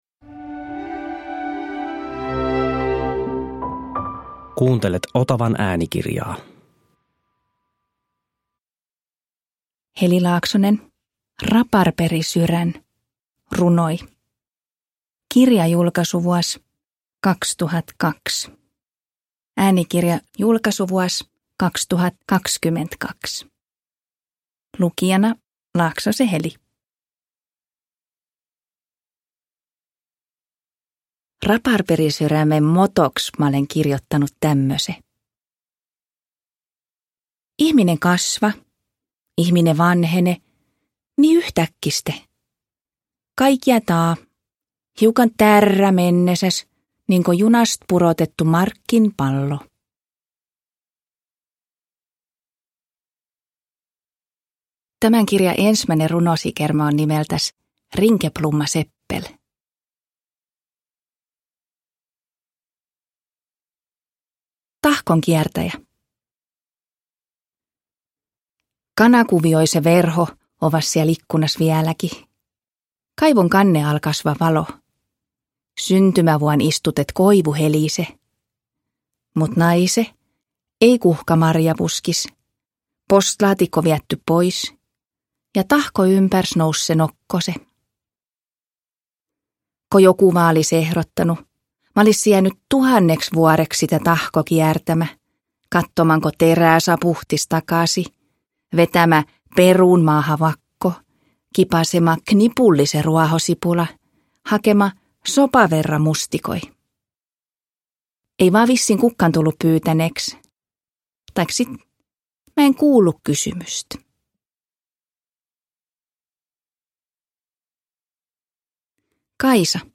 Raparperisyrän – Ljudbok – Laddas ner
Äänikirjan lukee Heli Laaksonen.
Uppläsare: Heli Laaksonen